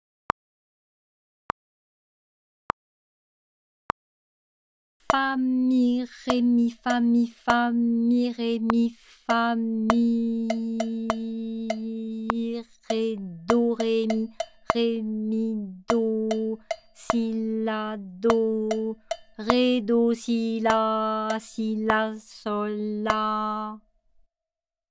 Polyrythmie